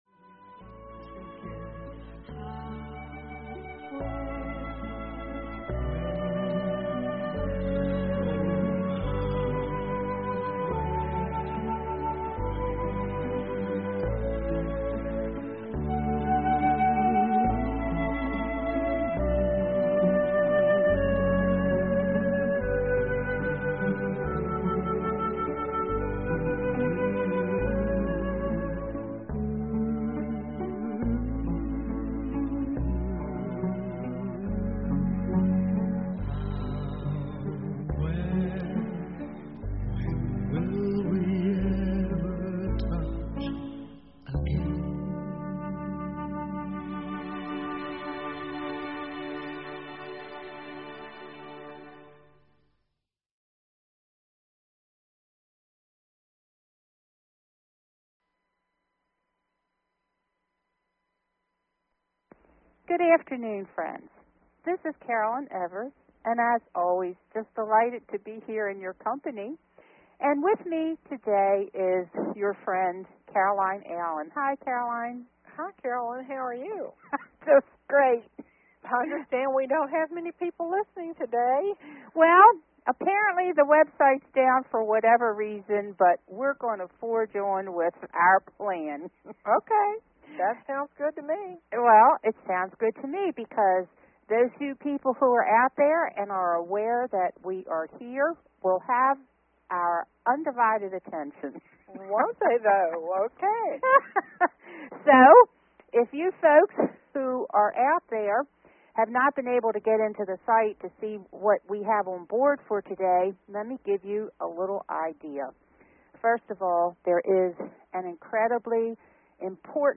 Talk Show Episode, Audio Podcast, The_Message and Courtesy of BBS Radio on , show guests , about , categorized as